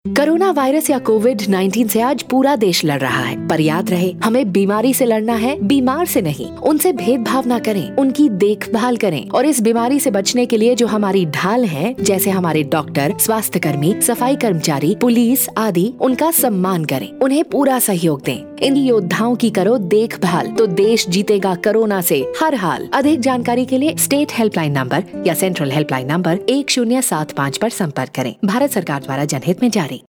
GoI_Caller tune_Anti-stigma_Hin.mp3